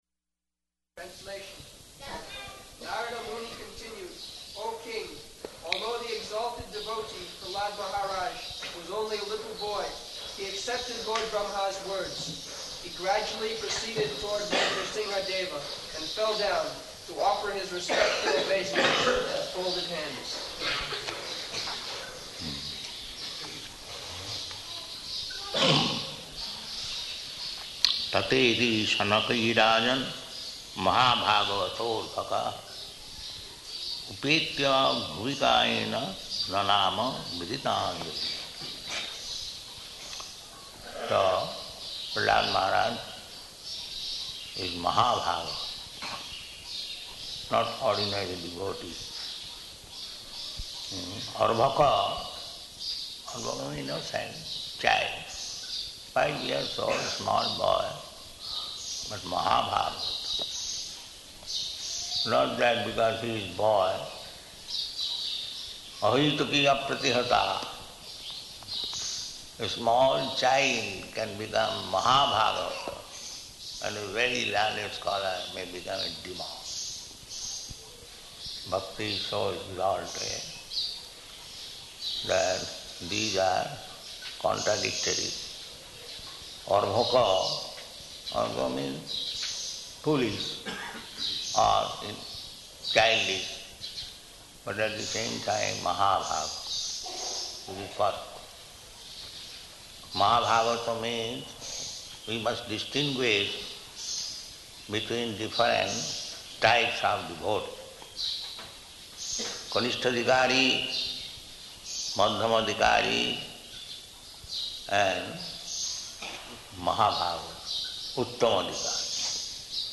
Location: Māyāpur